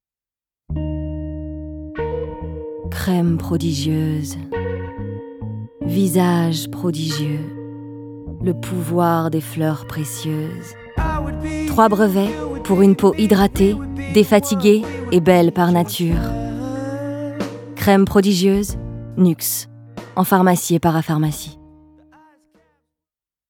bande démo Voix Off